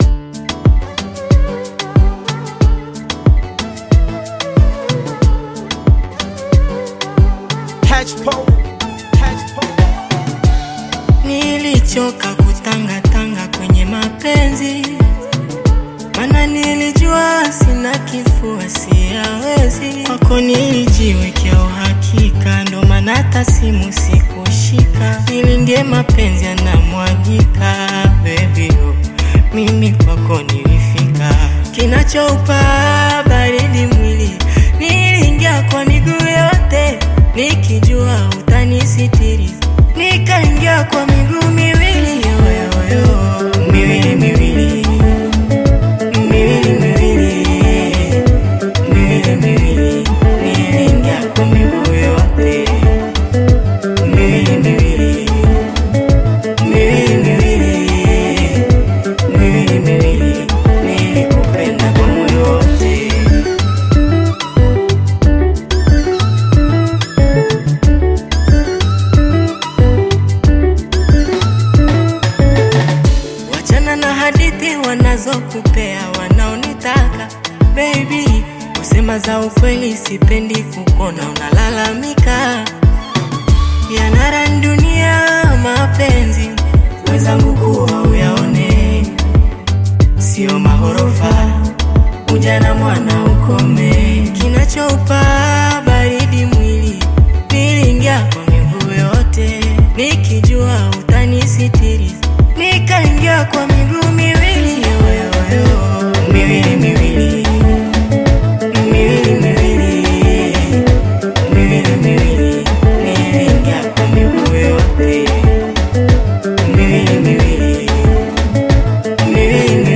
Bongo Flava music track
Tanzanian artist, singer, and songwriter
Bongo Flava song